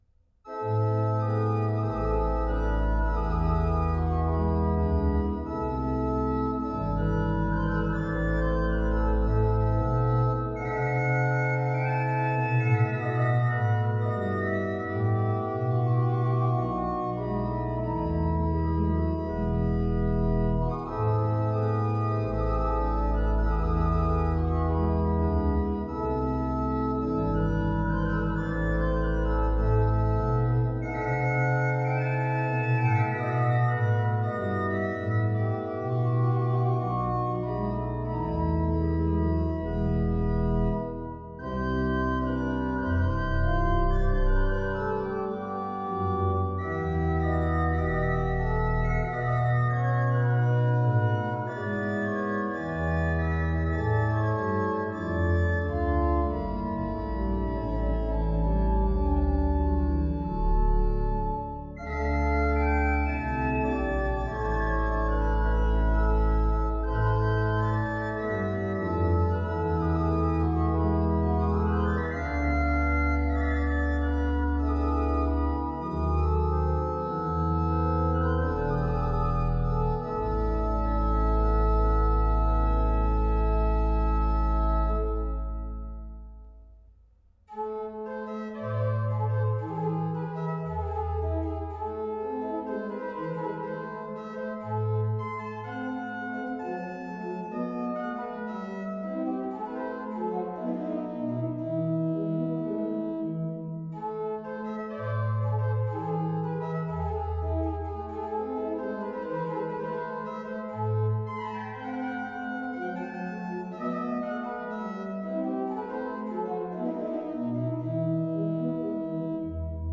Bach, Johann Sebastian - Aria variata in A minor, BWV 989 Free Sheet music for Harpsichord
Aria variata in A minor, BWV 989 Harpsichord version
Style: Classical